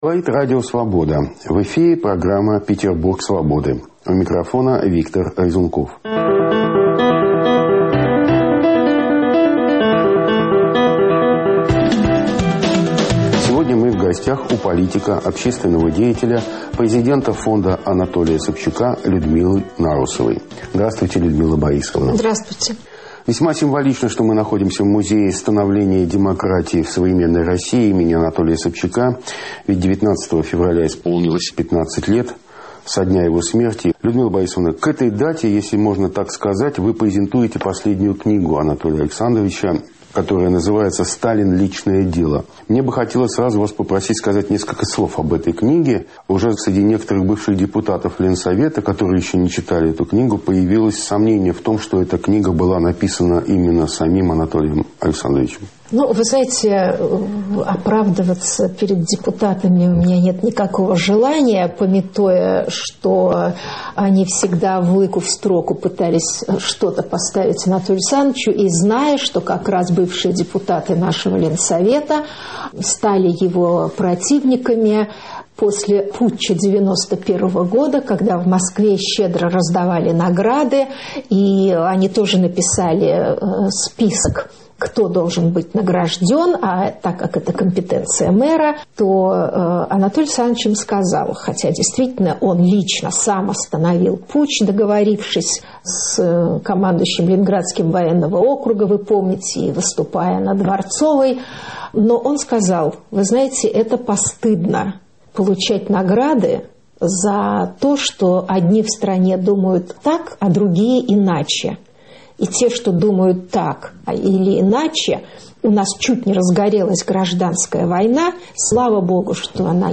В программе "Петербург "Свободы" беседуем с политиком, основателем Музея становления демократии в России им. А.Собчака, его вдовой Людмилой Нарусовой.